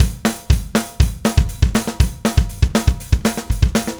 Power Pop Punk Drums 01b.wav